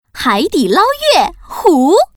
Index of /hall_shop/update/2892/res/sfx/common_woman/